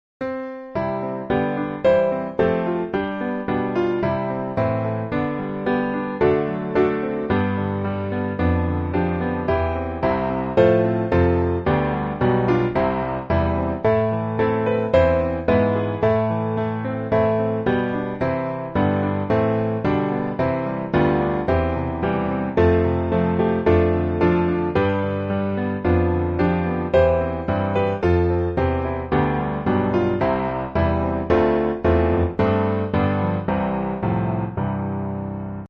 Piano Hymns
C Major